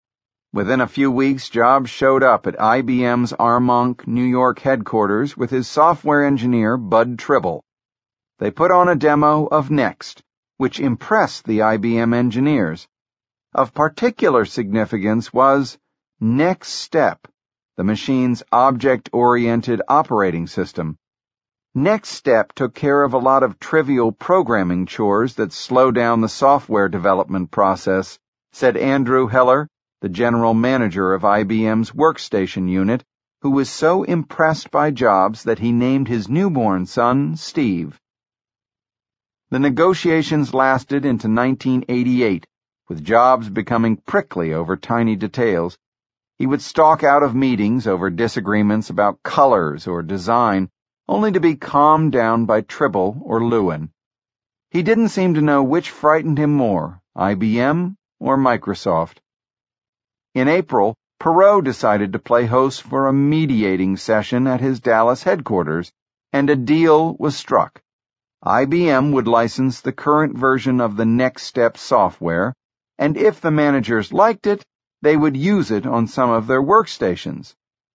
在线英语听力室乔布斯传 第245期:IBM(2)的听力文件下载,《乔布斯传》双语有声读物栏目，通过英语音频MP3和中英双语字幕，来帮助英语学习者提高英语听说能力。
本栏目纯正的英语发音，以及完整的传记内容，详细描述了乔布斯的一生，是学习英语的必备材料。